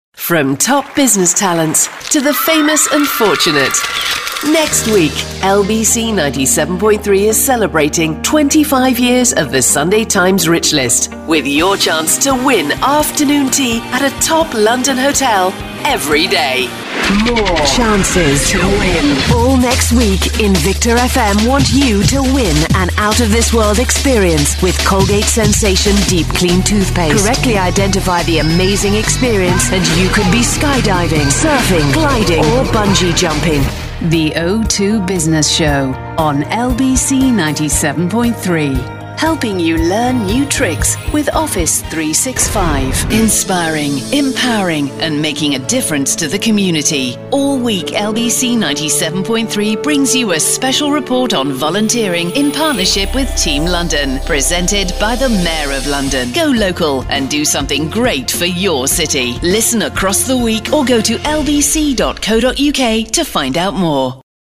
UK British Female Voiceover
PROMO & STATION BRANDING